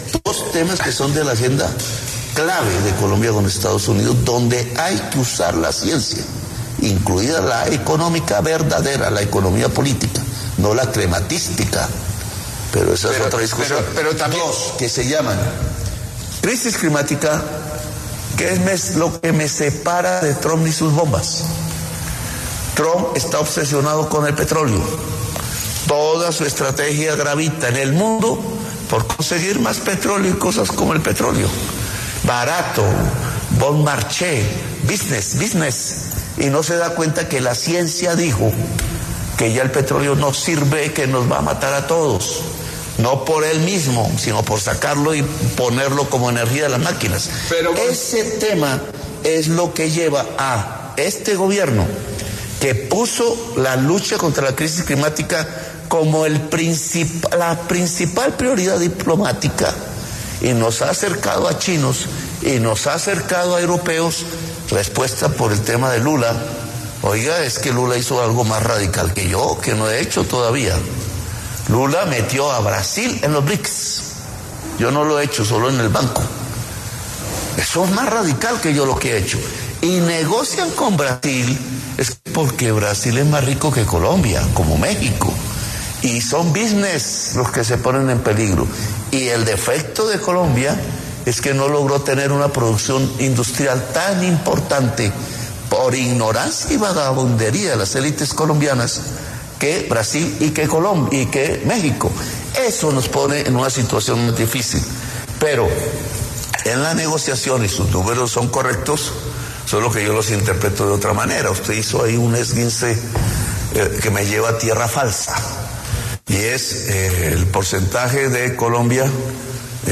El presidente de la República, Gustavo Petro, estuvo en entrevista con Daniel Coronell sobre diferentes temas de su Gobierno y se refirió a las diferencias que hay entre Estados Unidos y Colombia.